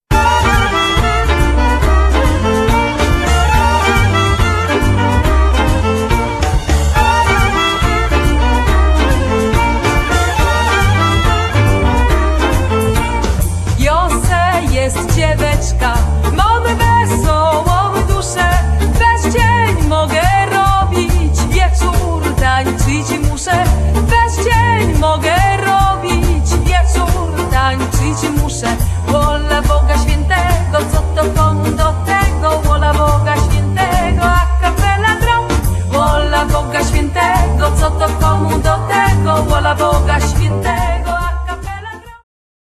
¶piew, skrzypce, złóbcoki, koza, fujarka
kontrabas, gitara basowa
saksofon sopranowy
perkusja, instrumenty perkusyjne